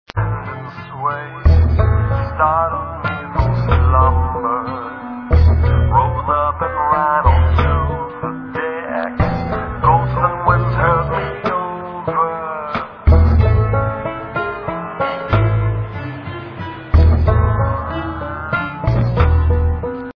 Mix of electronica/hip-hop/ambient